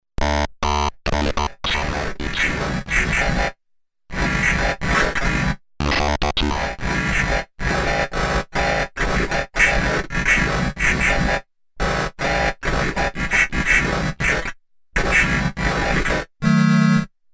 Digital delay with pitchshifting engine plus special tridimensional acoustic Roland RSS system.
ptch shifter 2
pitch.mp3